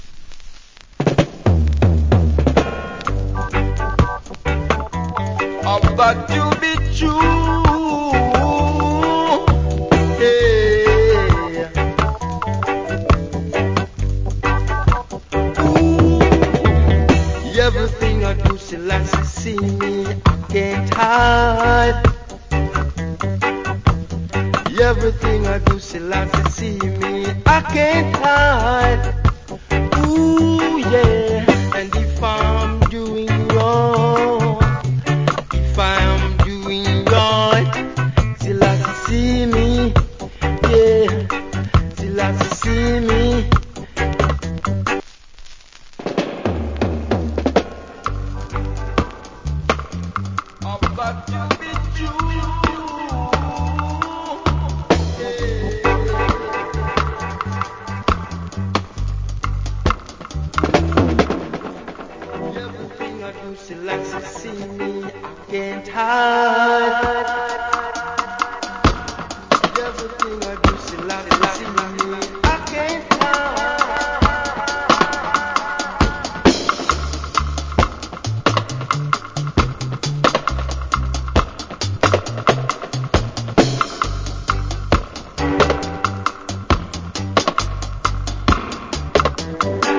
Nice Reggae Voval.